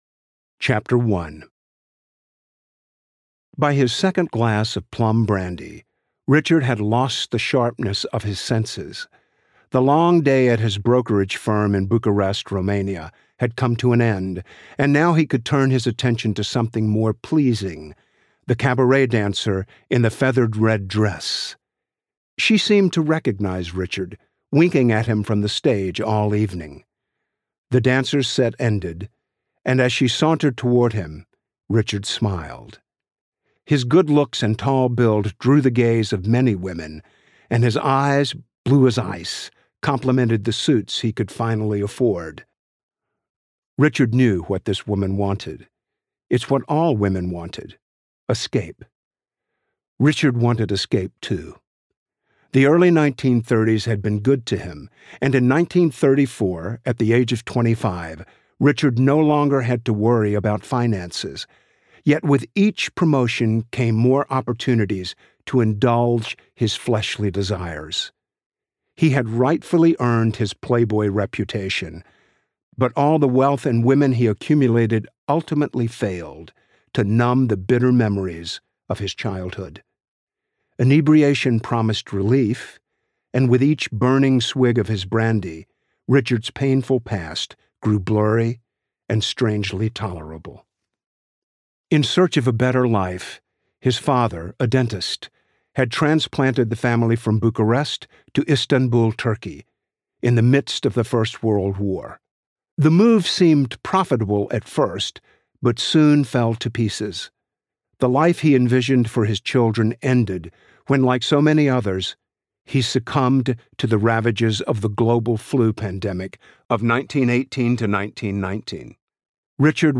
Wurmbrand Audiobook
10.3 Hrs. – Unabridged